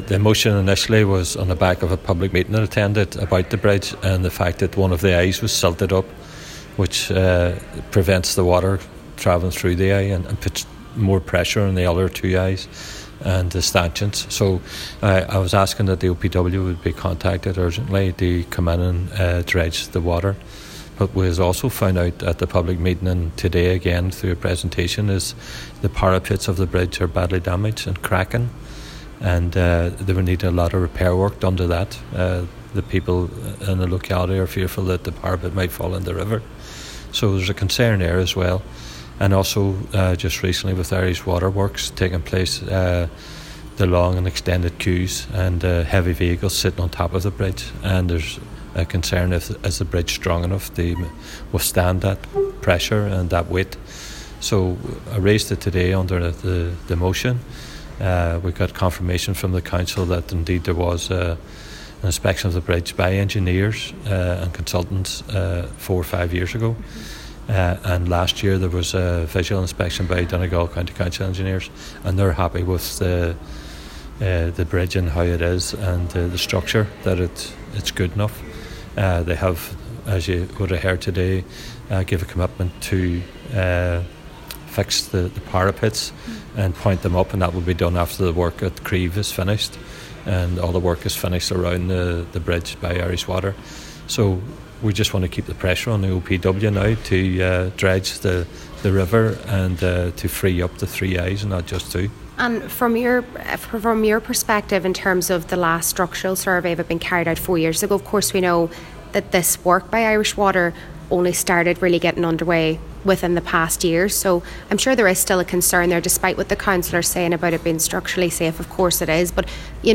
Cllr Gerry McMonagle says despite the local authority conducting a review four years ago, he believes another one is warranted: